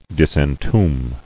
[dis·en·tomb]